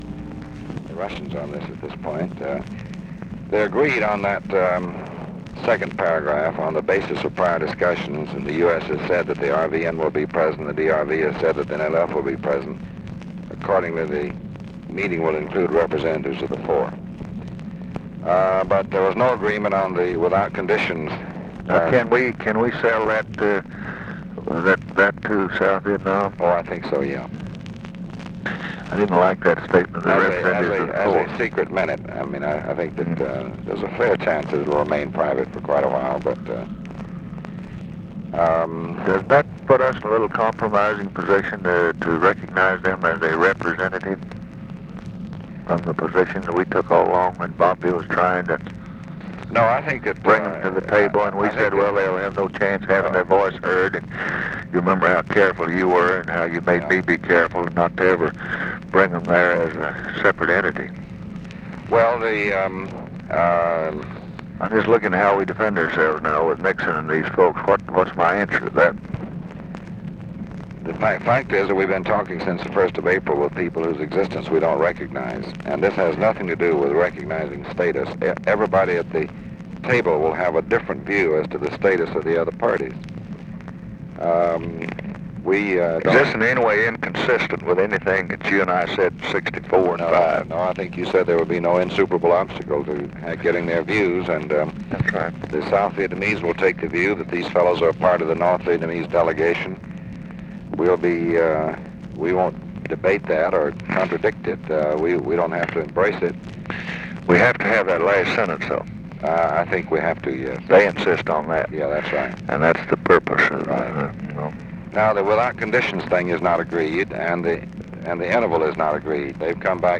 Conversation with DEAN RUSK, October 26, 1968